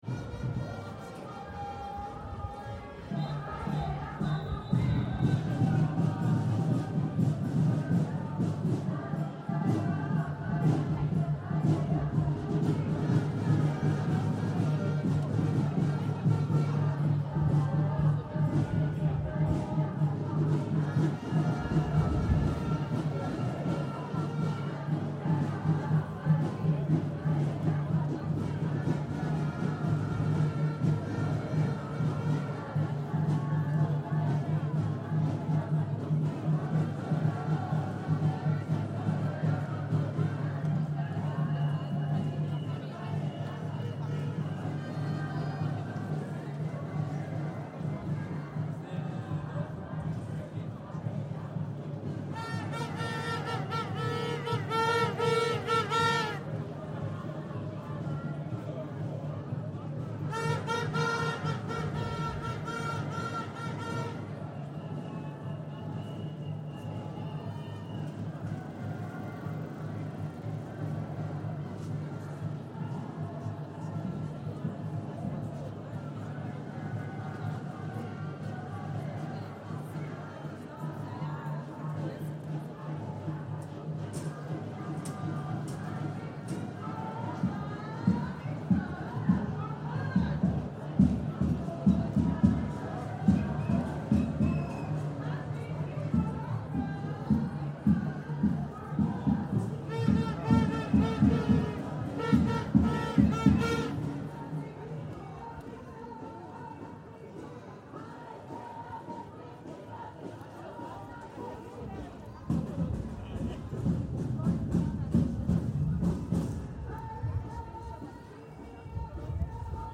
This recording is the intense sound from a "Bring them home"-protest march in Jerusalem, the crowd shouting, chanting,talking, demanding the Netanyahu-government do what's necessary to "bring home" the Israeli hostages.
The microphone is placed right in the centre of the large crowd, recording the extreme intensity and strong presence of frustrated people protesting their Israeli political leaders failing to do whats necessary to get the hostages home. The recording is of the sound of frustration and anger amongst a large part of Israeli people during Israels latest war with Hamas.
This recording is part of the HEYR project, presenting 3-dimensional soundscapes from special locations, connected to special events.